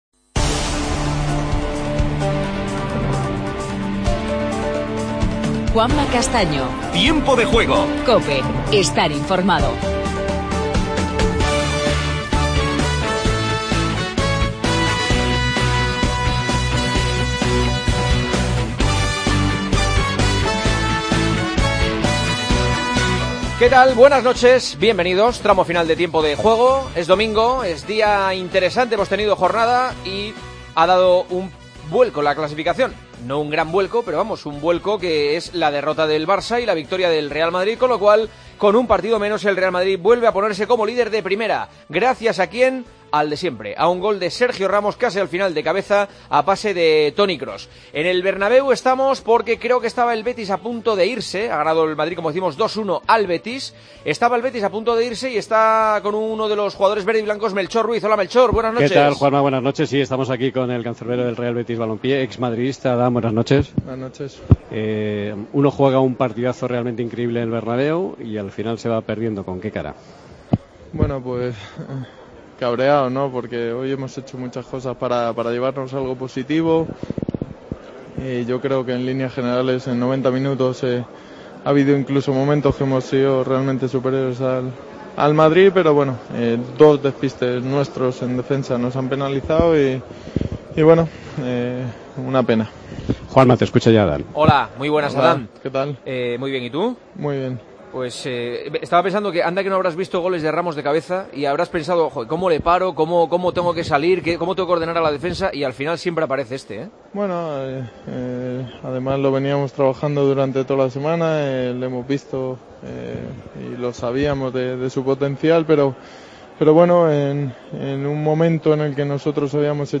El Real Madrid, nuevo líder tras ganar sufriendo al Betis. El Barcelona perdió ante el Deportivo 2-1. Entrevista a Adán y escuchamos a Modric y Sergio Ramos.